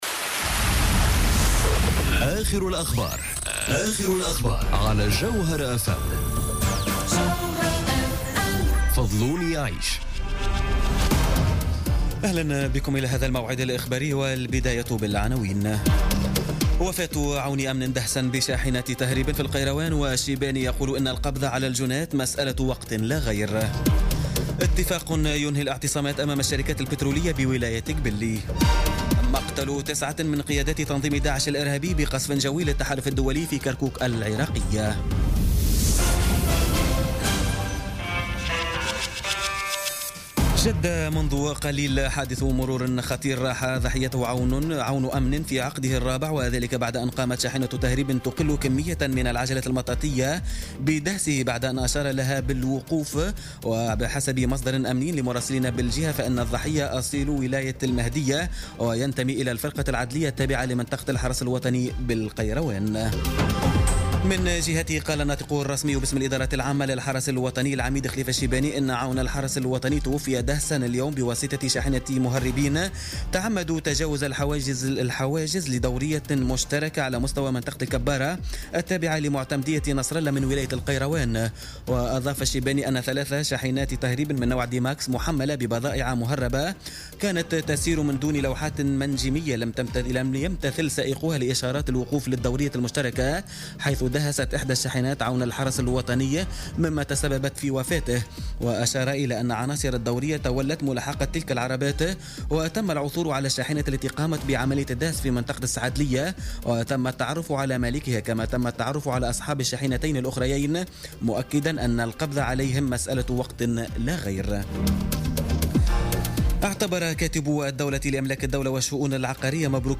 نشرة أخبار السابعة مساء ليوم السبت 26 أوت 2017